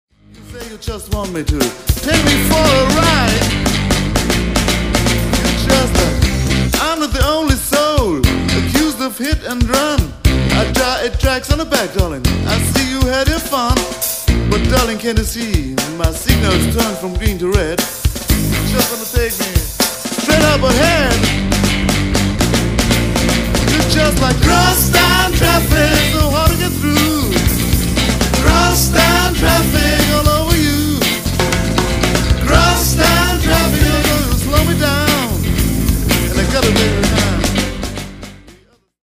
Recorded at Electric Sounddesign Studio, Linz/Austria 1999.
guitars, lead vocals
bass, vocals
drums